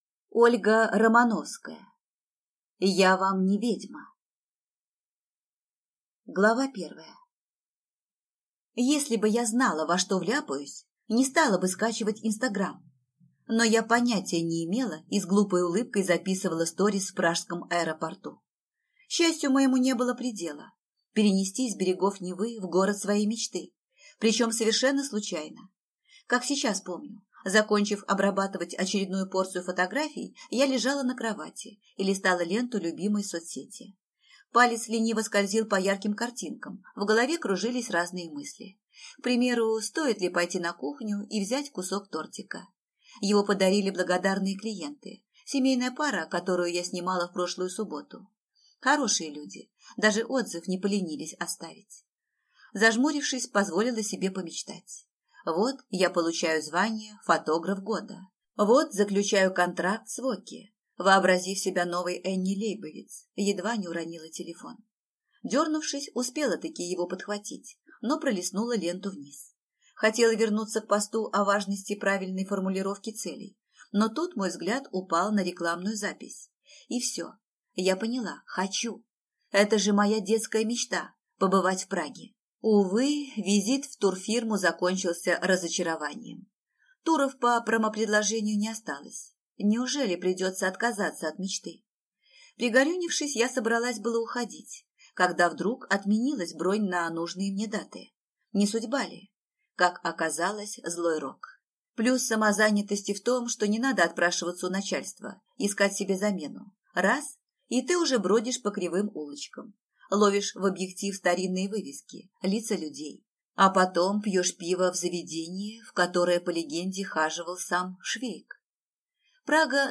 Аудиокнига Я вам не ведьма | Библиотека аудиокниг